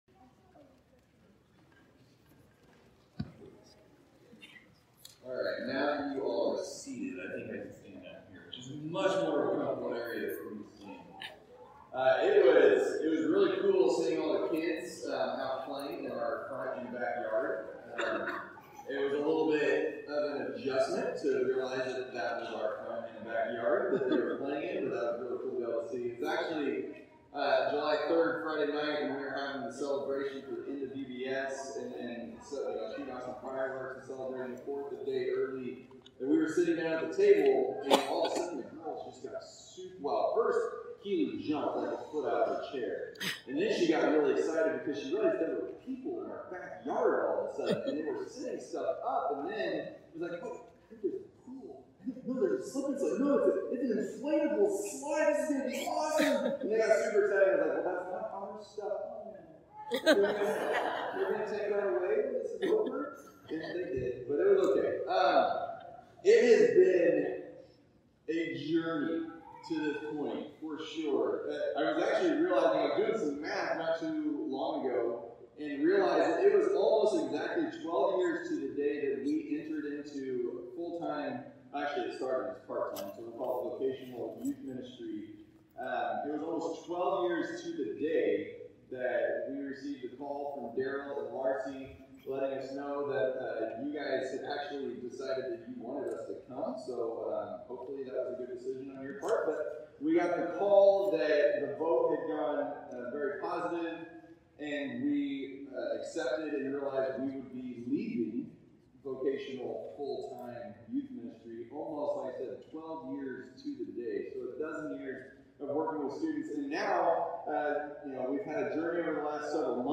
From Series: "Stand-alone Sermons"